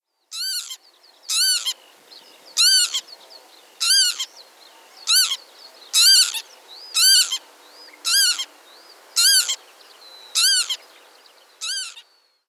Kiebitz
Flugrufe des Kiebitz
831-kiebitz_flugrufe-soundarchiv.com_.mp3